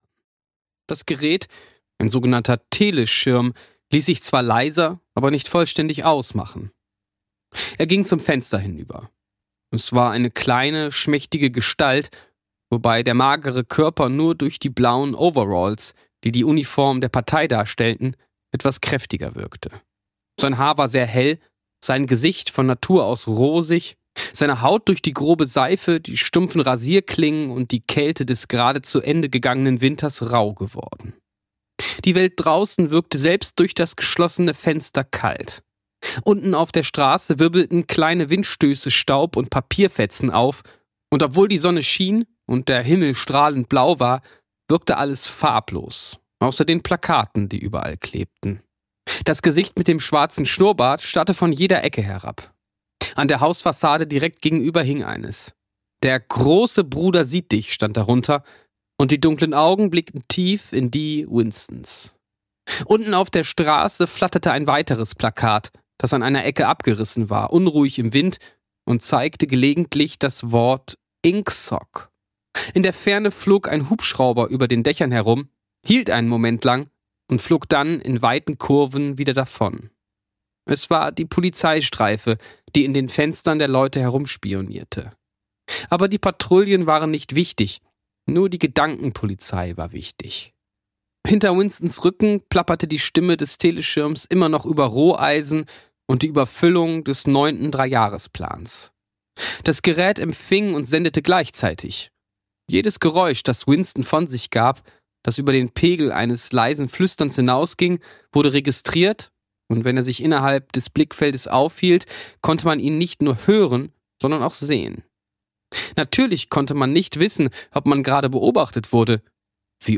Audio knihaGeorge Orwell: 1984, Farm der Tiere (DE)
Ukázka z knihy